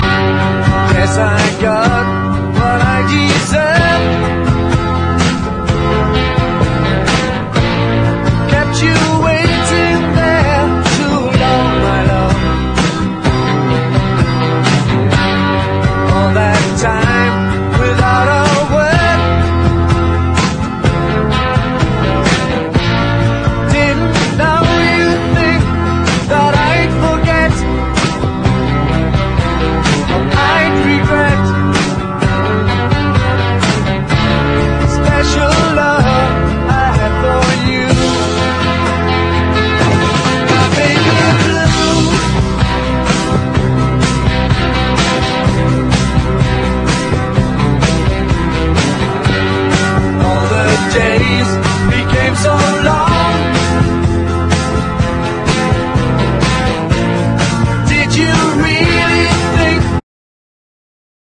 ROCK / 60'S
サイケデリック・ファンクな